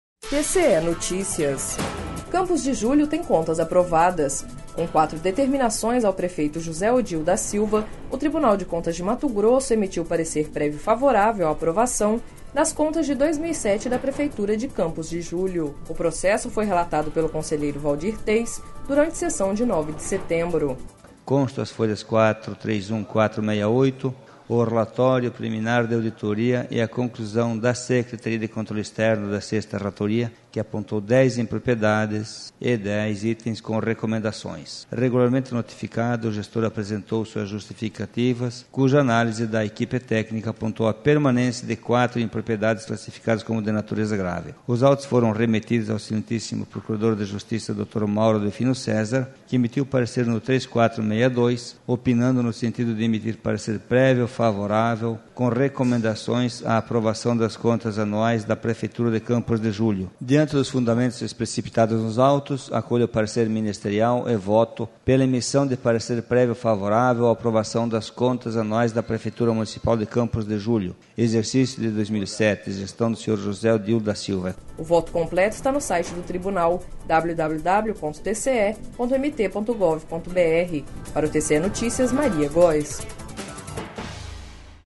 Sonora: Waldir Teis - conselheiro presidente do TCE-MT